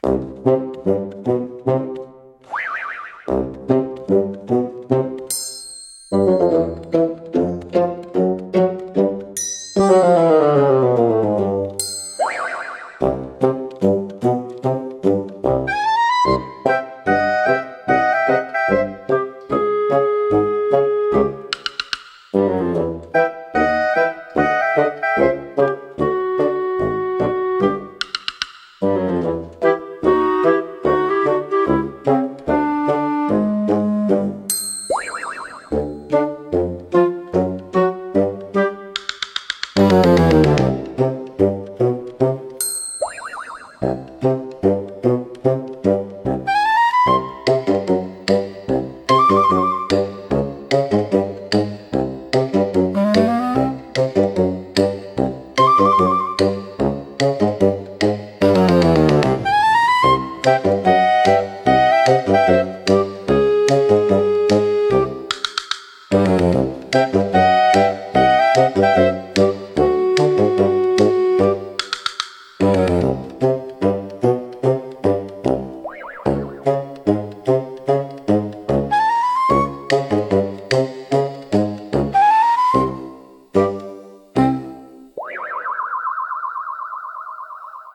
BGMセミオーダーシステムおとぼけは、バスーンとシロフォンを主体としたコミカルでドタバタした音楽ジャンルです。